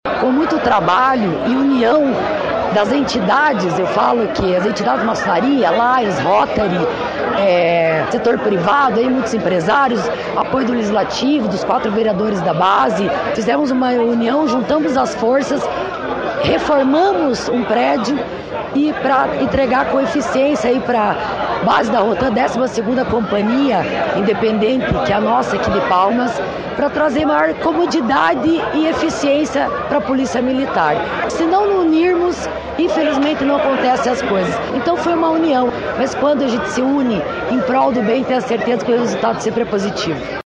Sonora da prefeita de Clevelândia, Rafaela Losi, sobre a nova base da Rotam no município